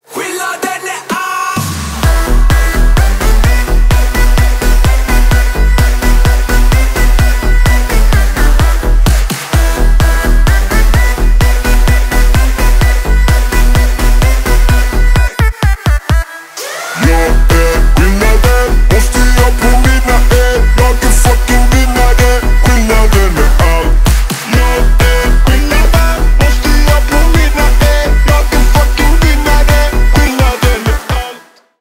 мужской голос
ритмичные
громкие
веселые
заводные
dance
Electronic
EDM
качающие
энергичные
Big Room